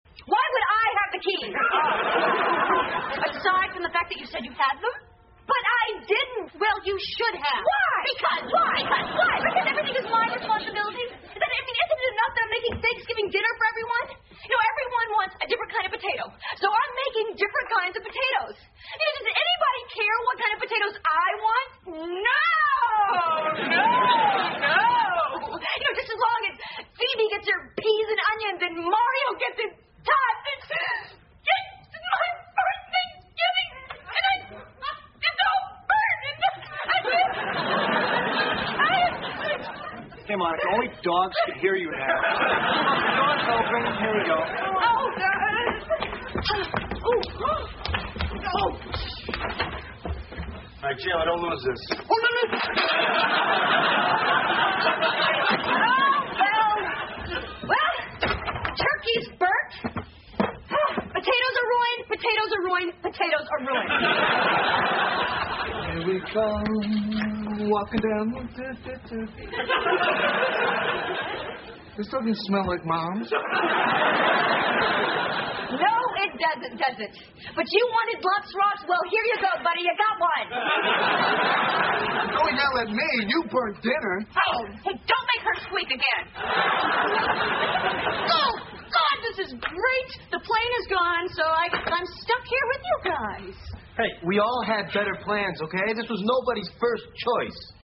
在线英语听力室老友记精校版第1季 第110期:气球飞走了(11)的听力文件下载, 《老友记精校版》是美国乃至全世界最受欢迎的情景喜剧，一共拍摄了10季，以其幽默的对白和与现实生活的贴近吸引了无数的观众，精校版栏目搭配高音质音频与同步双语字幕，是练习提升英语听力水平，积累英语知识的好帮手。